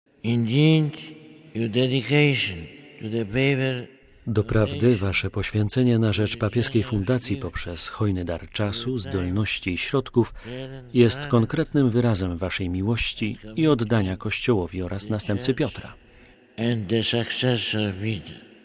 Jan Paweł II podziękował członkom Fundacji Papieskiej za wspieranie jego duszpasterskiej posługi. Ich 50-osobową grupę przyjął na audiencji w Sali Klementyńskiej: